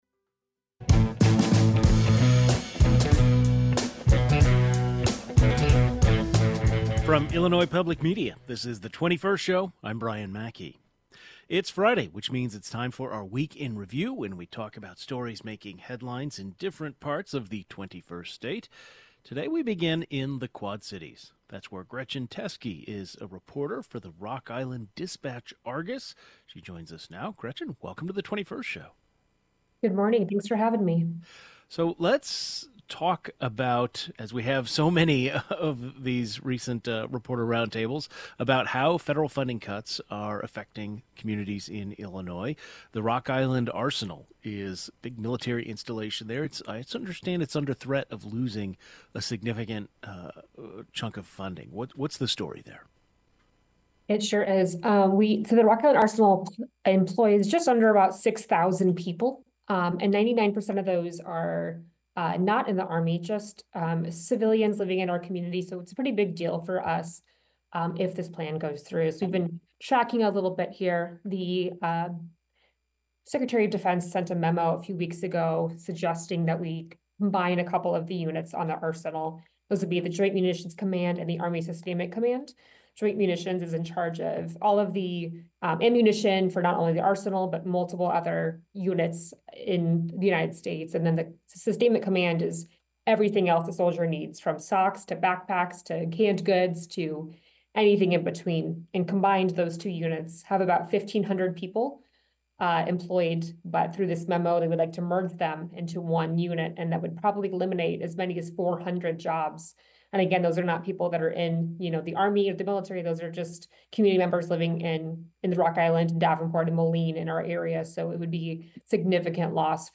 In this week's Reporter Roundtable segment, we discuss how federal funding cuts will impact the Quad Cities and what's going on with big businesses in the area such as John Deere and Amazon. A local newspaper reporter has the latest.